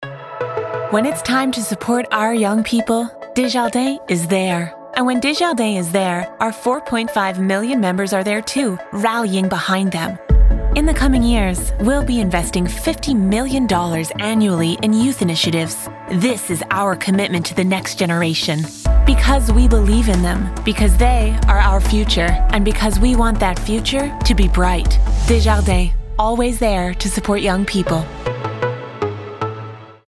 Publicité (Desjardins) - ANG